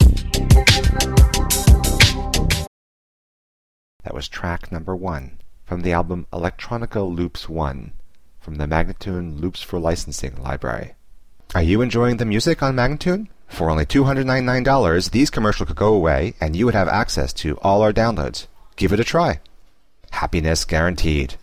Instrumental samples in many genres.
090-C-ambient:teknology-1024